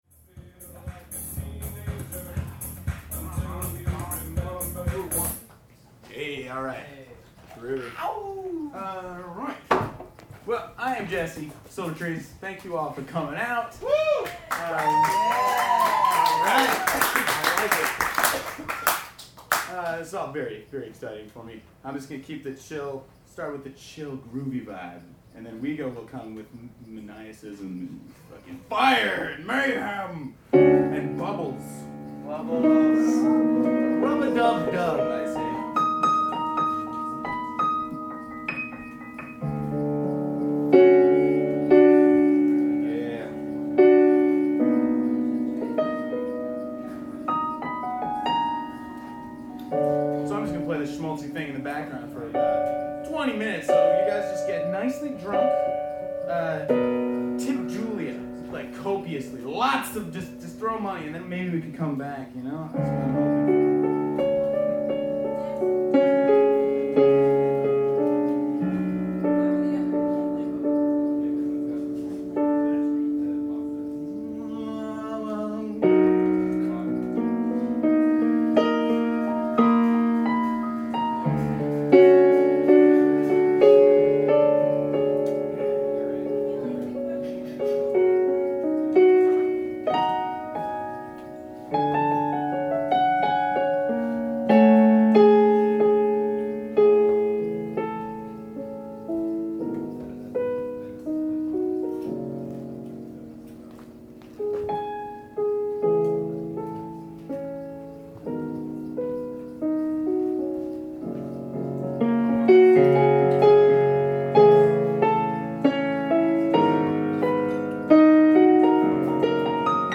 solo set of piano and voice